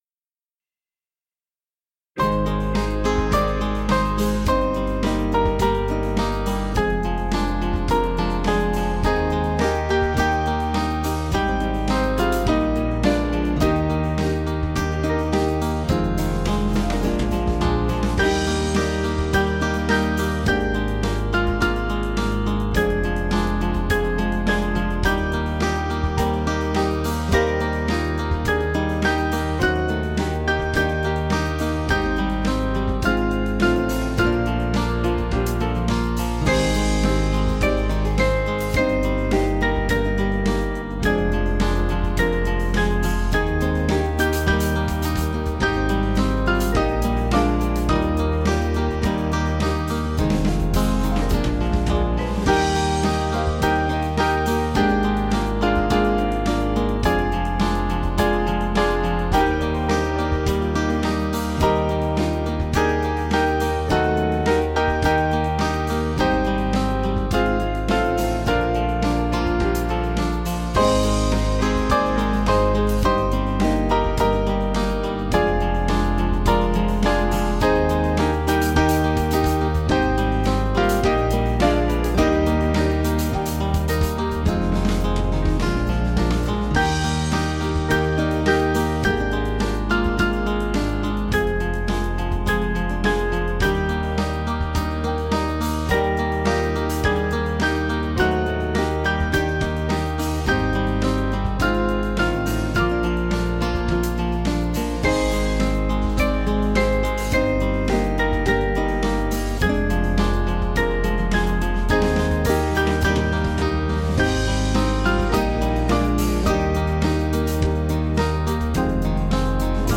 Small Band
(CM)   5/Eb